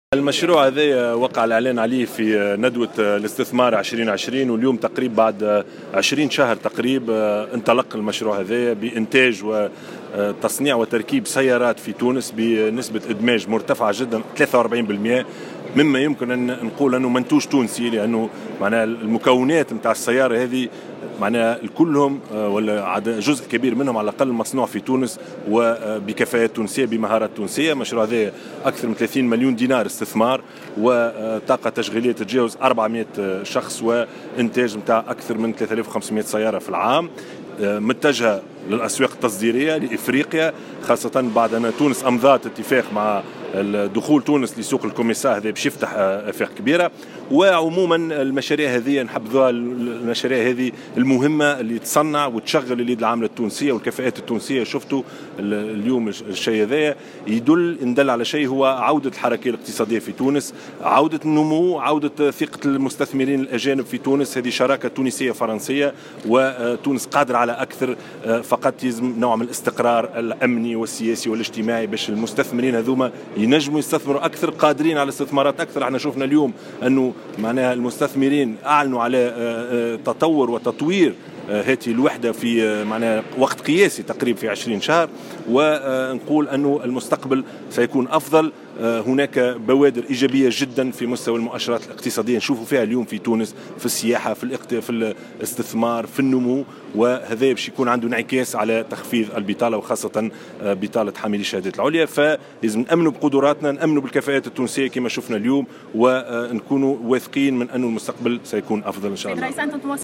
وفي تصريحه لـ "الجوهرة أف أم"، قال الشاهد إن هذا المشروع تم الاعلان عنه في ندوة الاستثمار التي نظمتها الحكومة منذ اكثر من عام ويأتي في اطار شراكة تونسية فرنسية.